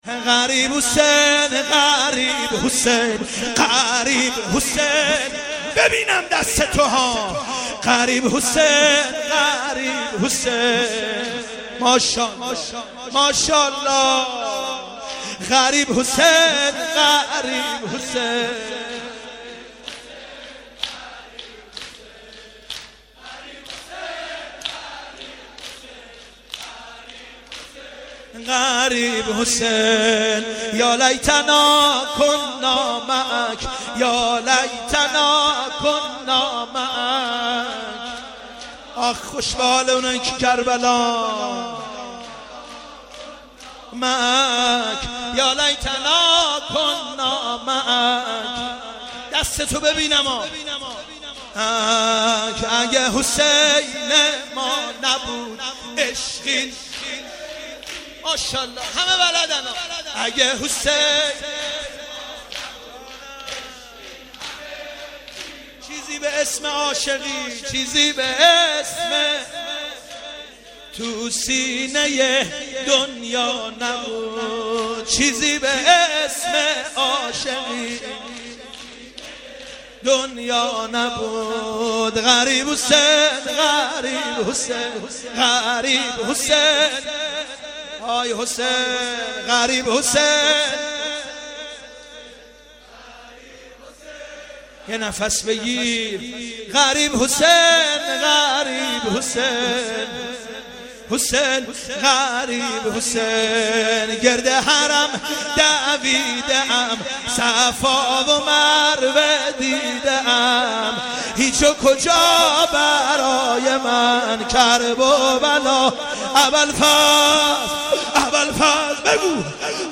مناسبت : اربعین حسینی
قالب : شور